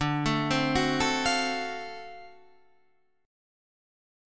D7#9 Chord